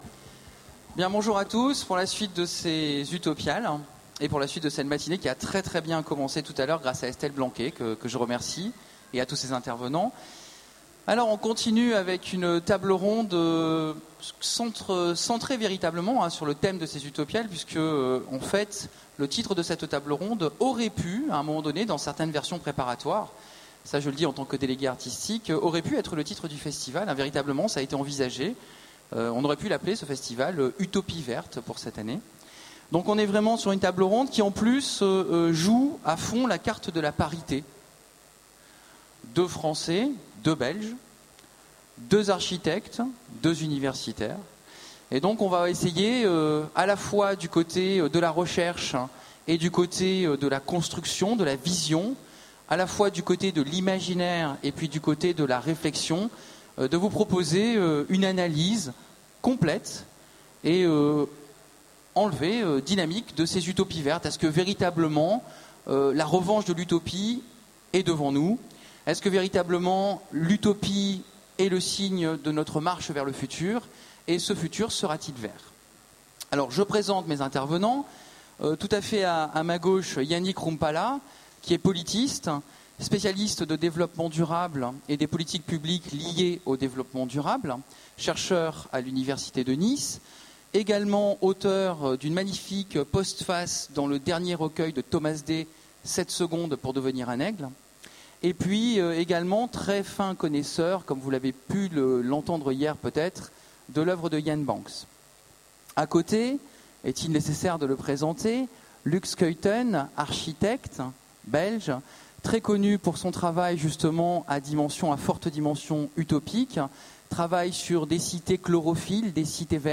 Utopiales 13 : Conférence Les utopies vertes